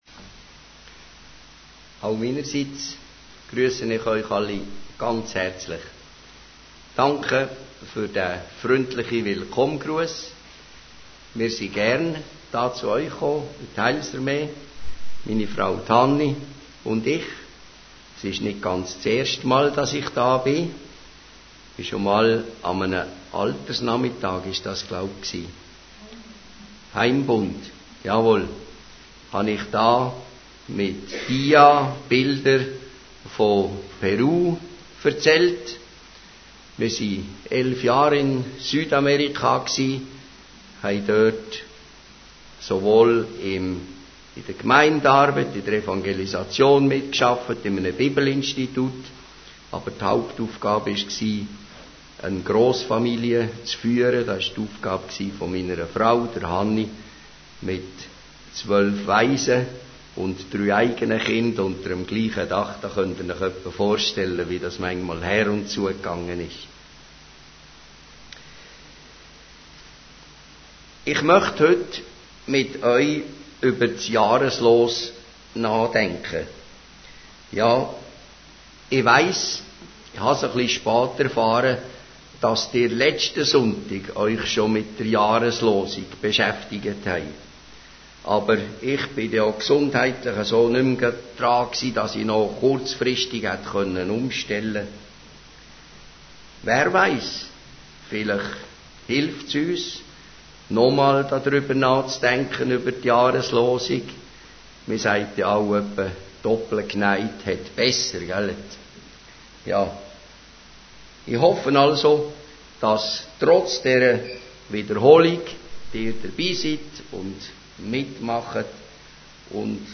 Speaker: Gastprediger Details Series: Allgemeine Predigten Date: 2010-01-10 Hits: 7111 Scripture: BibleGateway John 14:1 Duration: 30:09 Download Audio